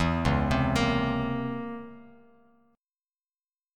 C#mM13 chord